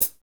HAT A C PE0A.wav